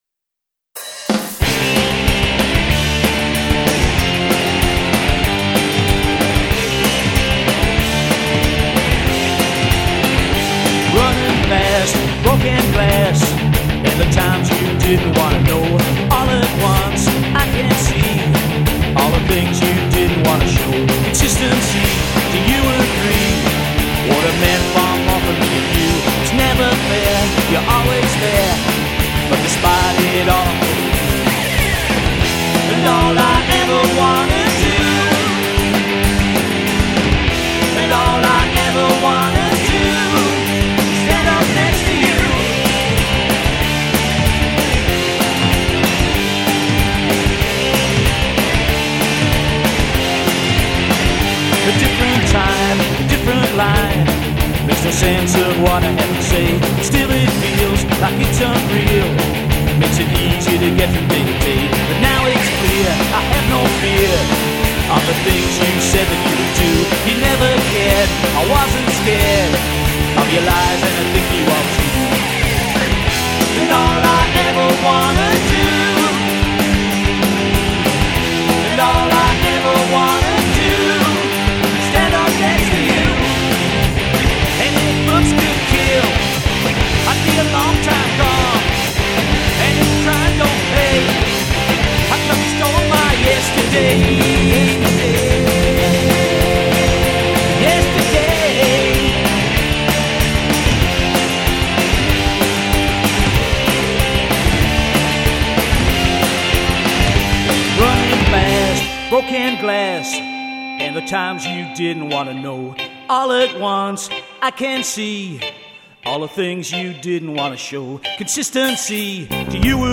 Bass, vocals
Guitar, vocals
Drums